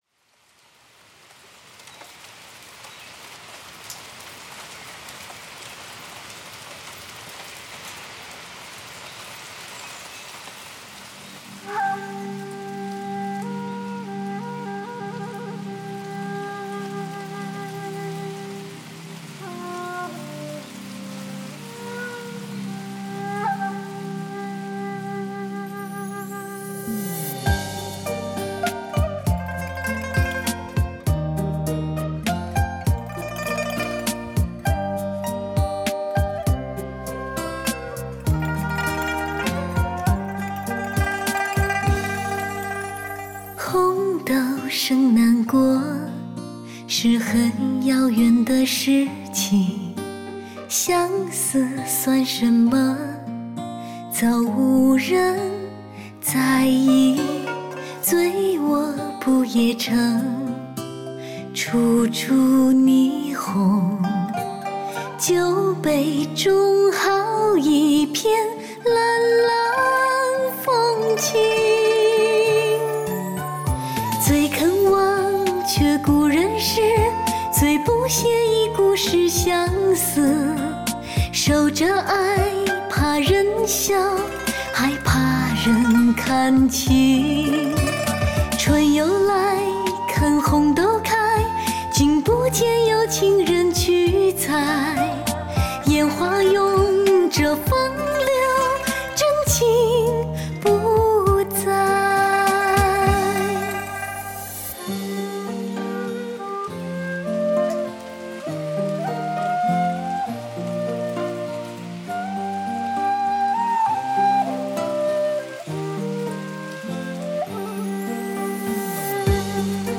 首屈一指美国DTS-ES 6.1超级解析制造，七声道分离定位无衰减音频编码处理。
神奇多声道“立体剪裁创意空间”，体验人声、乐器、音效完美合理分布。